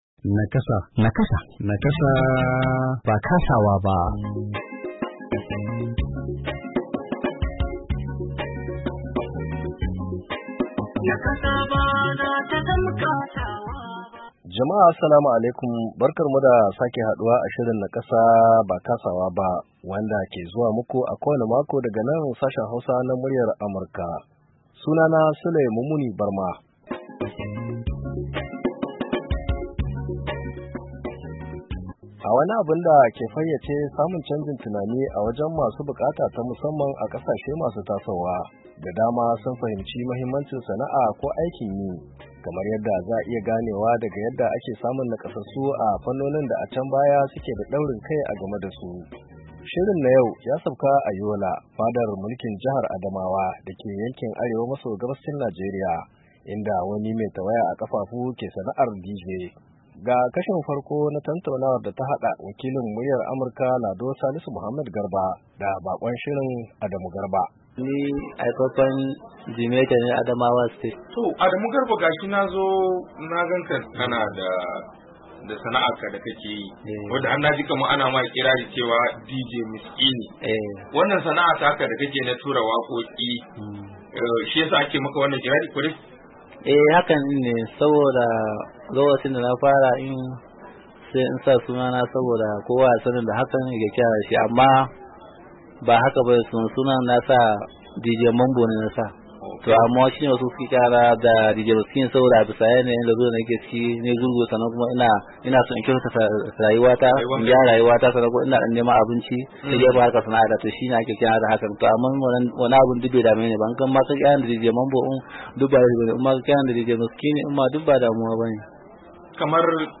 NIAMEY, NIGER - A shirin Nakasa na wannan makon,mun sauka ne a Yola fadar mulkin jihar Adamawa dake yankin Arewa maso gabashin Najeriya inda muka tattauna da wani mai nakasa mai sana’ar DJ.